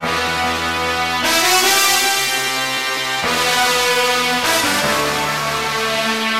描述：铜管乐器一样的合成物
Tag: 75 bpm Weird Loops Synth Loops 1.08 MB wav Key : E